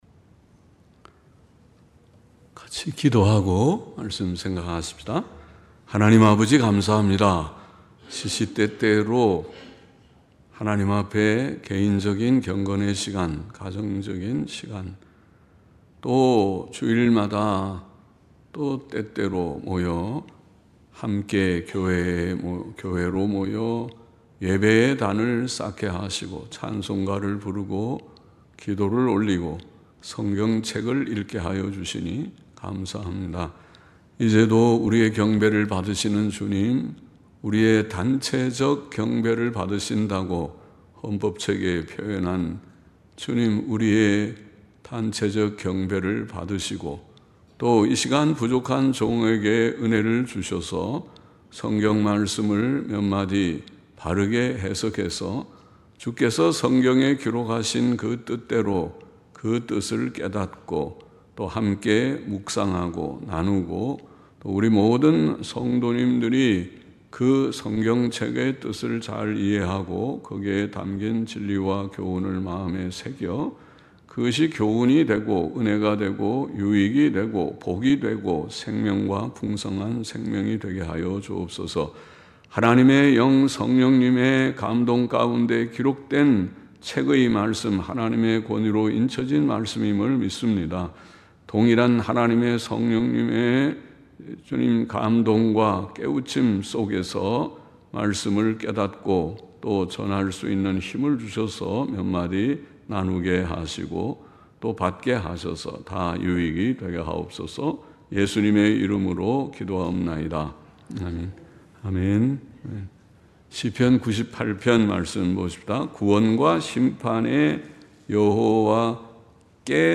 주일오전설교